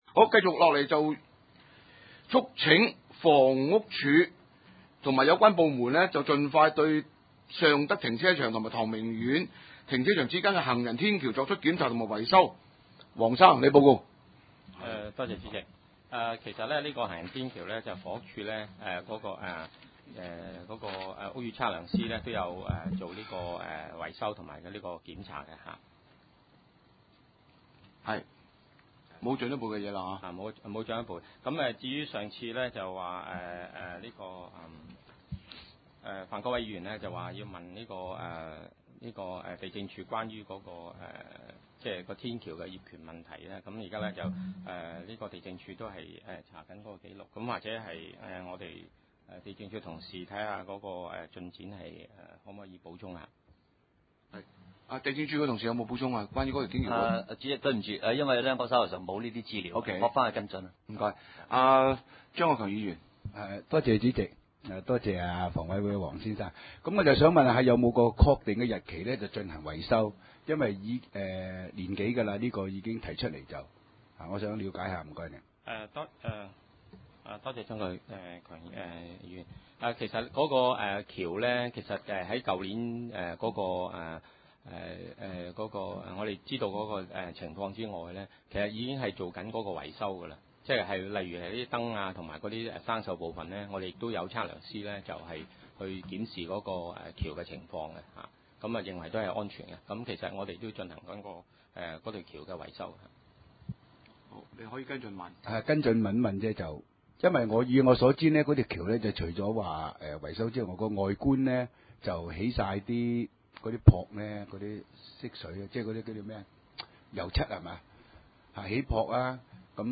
房屋及環境衞生委員會會議
地點：西貢區議會會議室